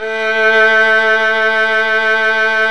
Index of /90_sSampleCDs/Roland - String Master Series/STR_Violin 1-3vb/STR_Vln2 % marc
STR  VL A 4.wav